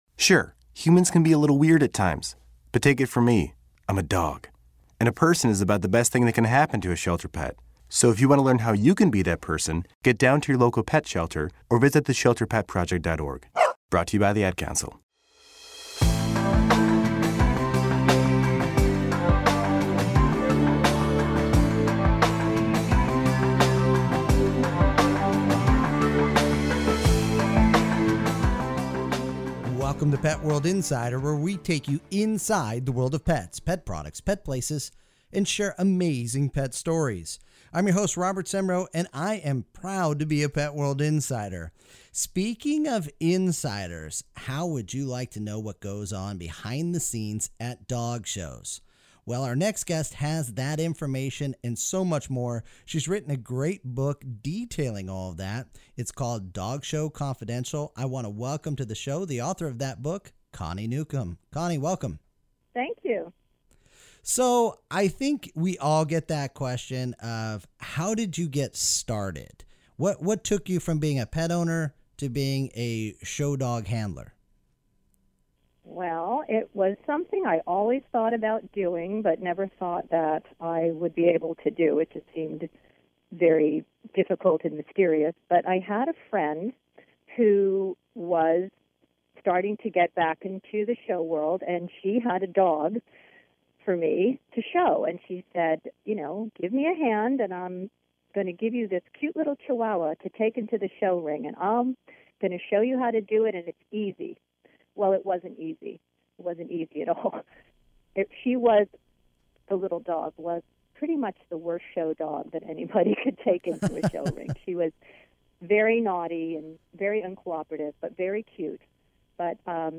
Pet World Insider Radio Segment